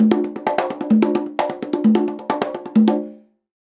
130BONGO 13.wav